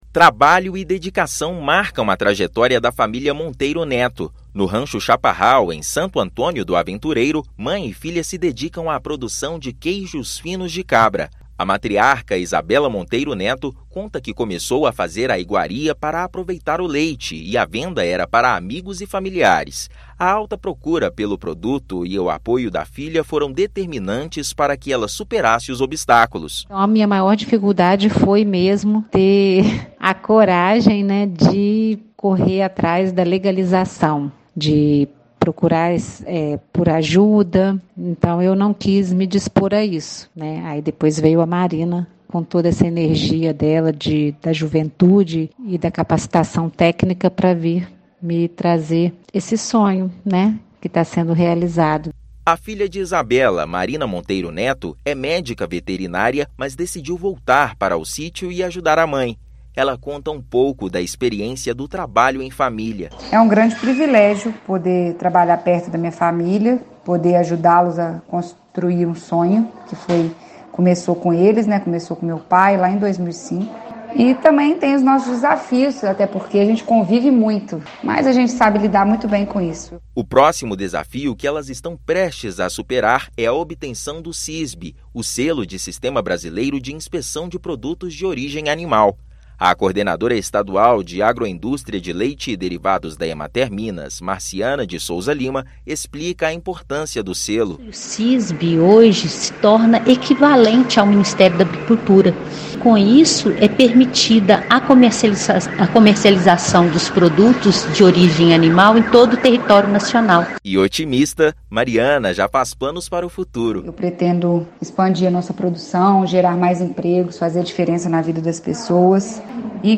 Empreendedorismo feminino na agricultura familiar: mãe e filha se destacam na produção de queijos finos de cabra. Ouça matéria de rádio.